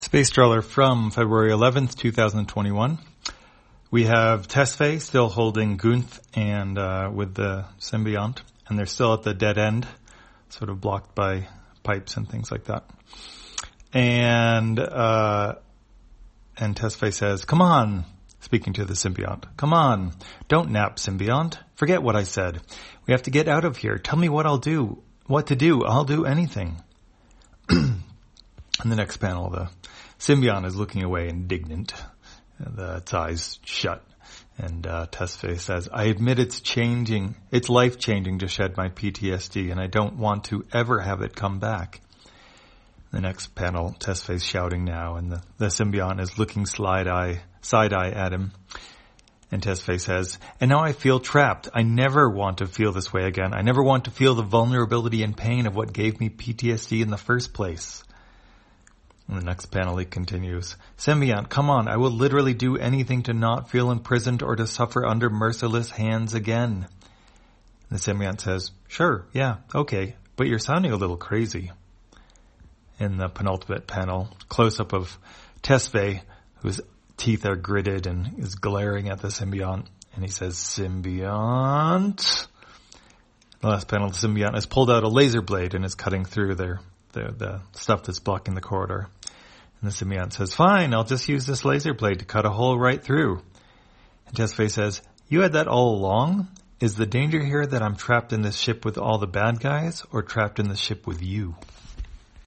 Spacetrawler, audio version For the blind or visually impaired, February 11, 2021.